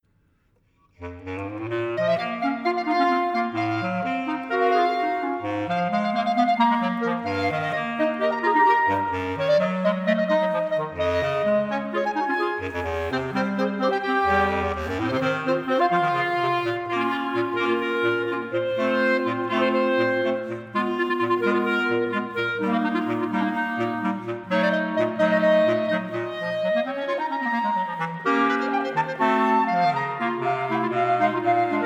eine Viererformation von bestechender Virtuosität.